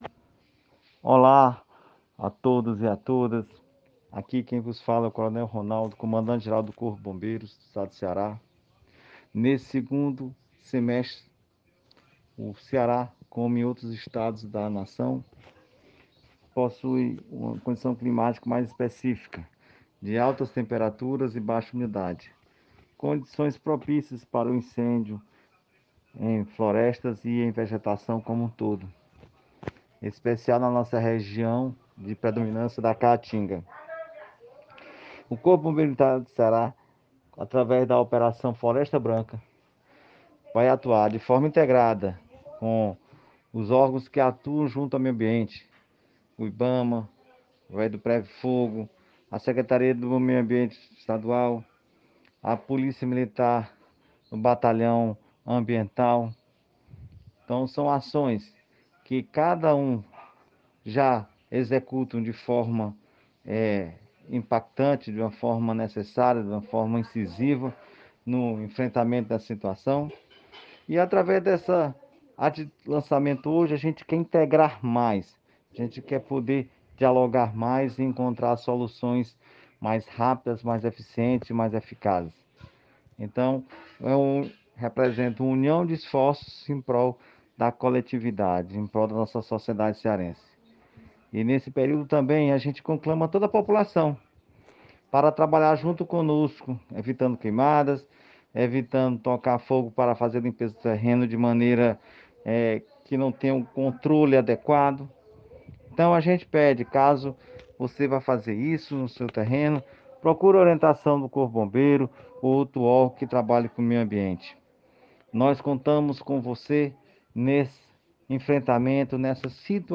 Áudio do Comandante-Geral, Coronel Ronaldo Roque de Araújo